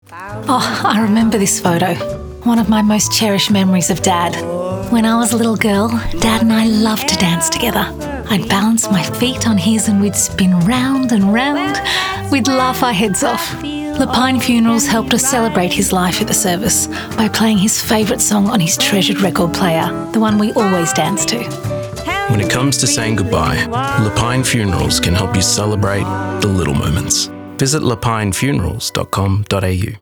A warm, rich and intelligent voice.
Voice over reel
Le Pine Funerals radio ad
Le-Pine-Father-and-Daughter-Dancing-30sec-Radio-REV2.mp3